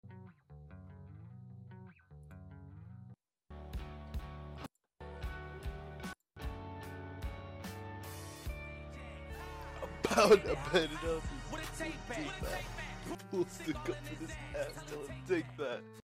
compression just demolished the quality but whatevr